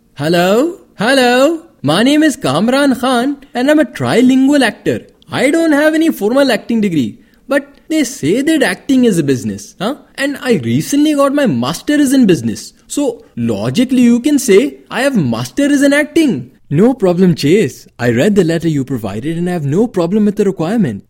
A unique voice that can be tailored to ensure the effective delivery of a variety of tones ranging from authoritative to friendly for a wide range of products and services.
Sprechprobe: Sonstiges (Muttersprache):